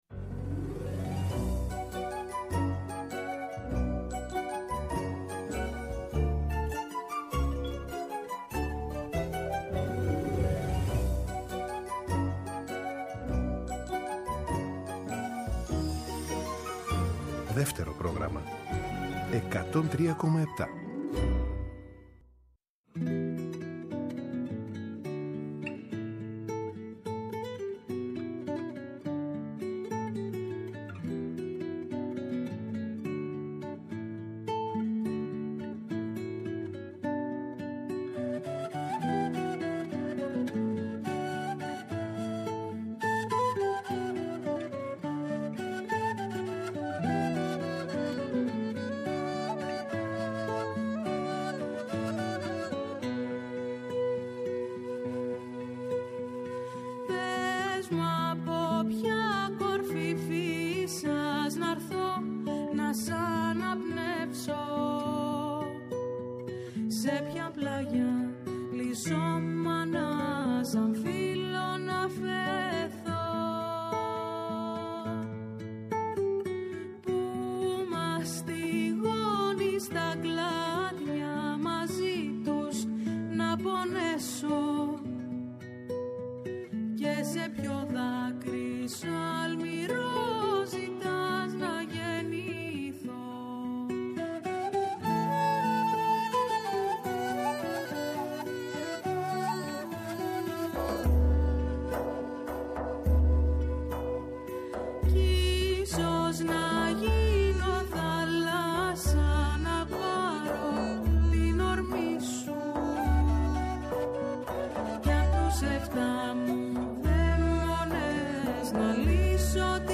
ΔΕΥΤΕΡΟ ΠΡΟΓΡΑΜΜΑ Συνεντεύξεις